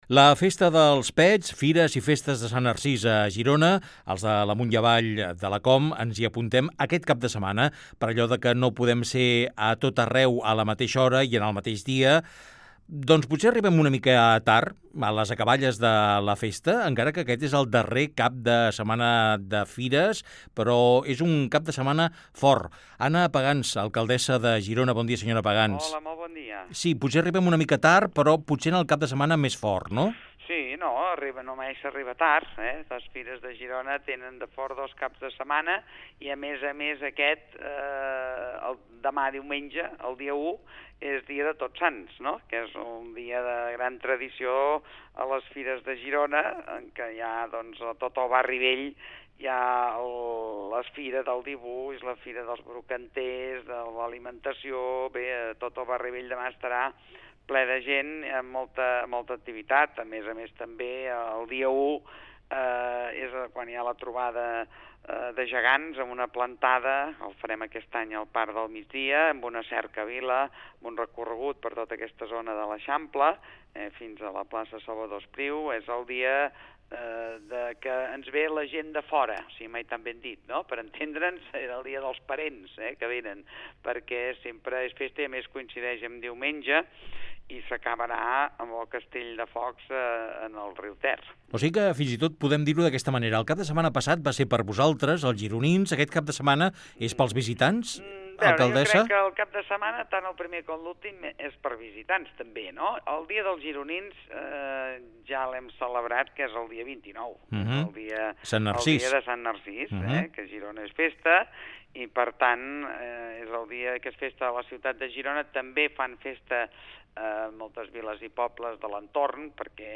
Fragment d'una entrevista a l'alcaldessa de Girona, Anna Pagans, amb motiu de les Fires de Girona.